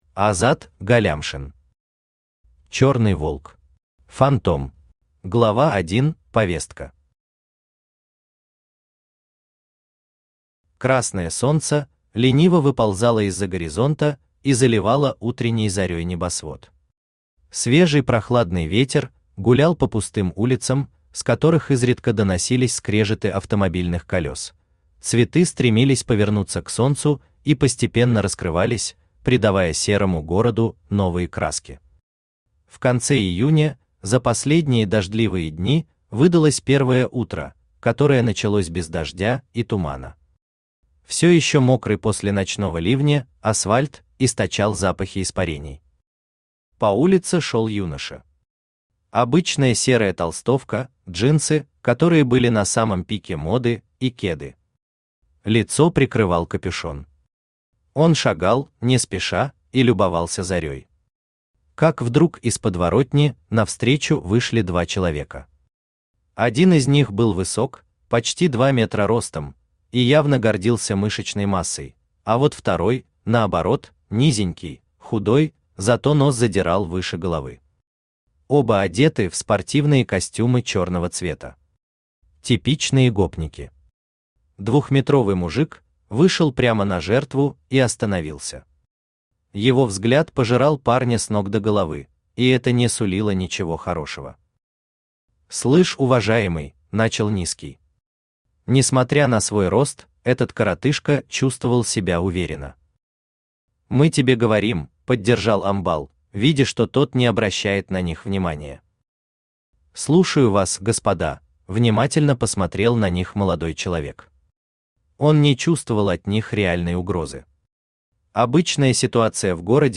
Фантом Автор Азат Магавиевич Галлямшин Читает аудиокнигу Авточтец ЛитРес.